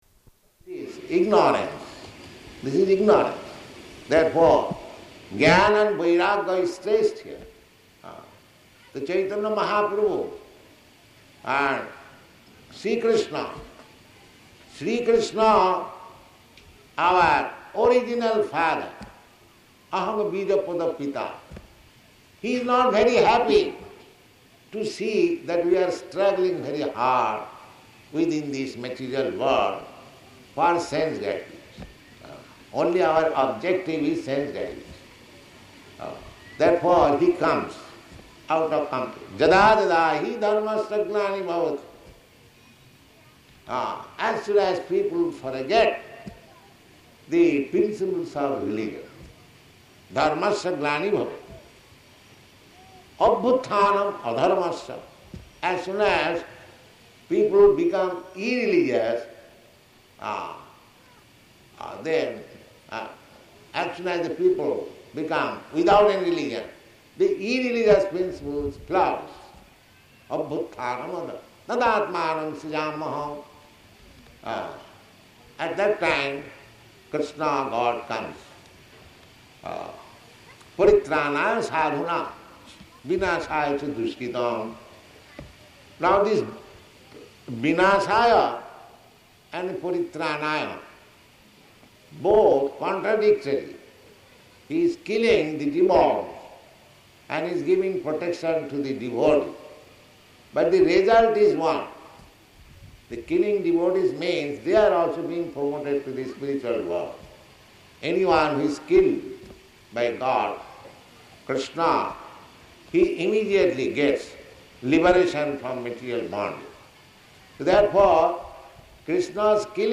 Lecture [partially recorded]
Lecture [partially recorded] --:-- --:-- Type: Lectures and Addresses Dated: March 27th 1972 Location: Bombay Audio file: 720327LE.BOM_hHZuHnG.mp3 Prabhupāda: ...ignorance, this is ignorance.